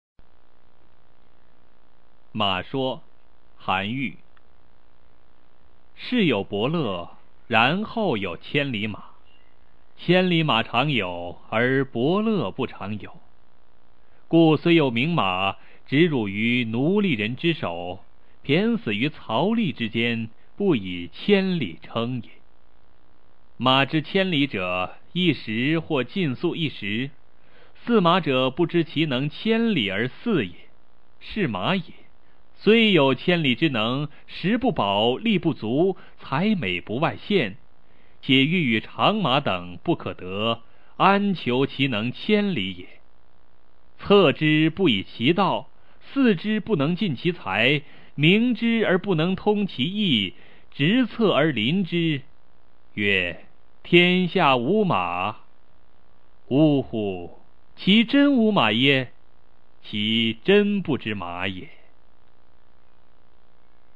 《马说》原文与译文（含赏析、朗读）　/ 韩愈